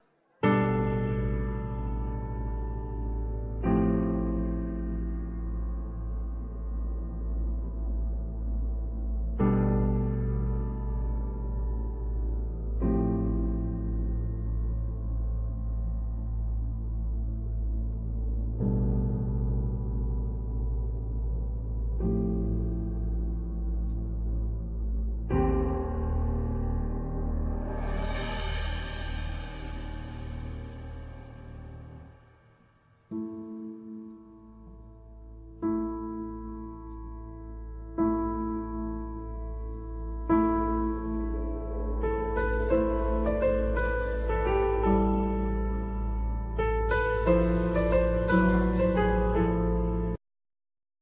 Guitar(Spanish,Electric,Acoustic),Banjo,Percussions
Flauta de adelfa,Tin whistle,Fairlight III
Piano
Cello
Vocals